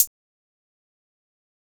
Closed Hats
Waka HiHat - 1 (1).wav